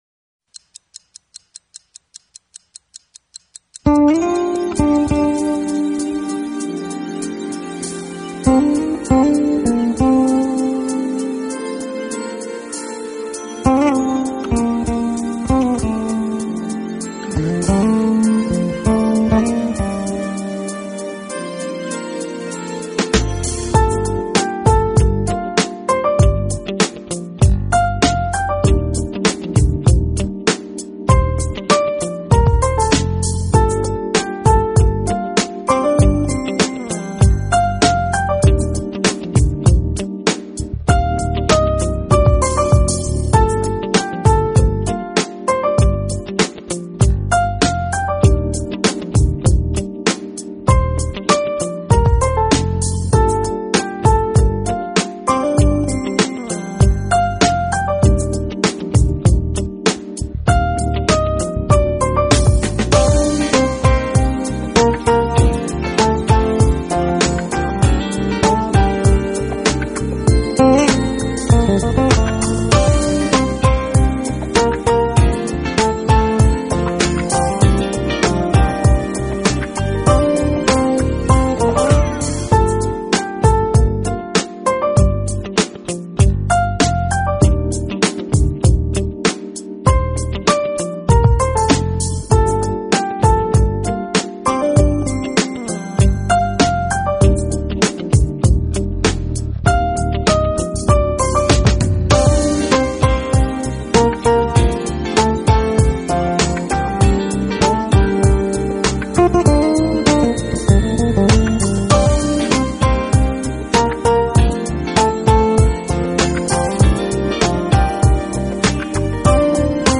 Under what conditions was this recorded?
Quality: MP3 / Joint Stereo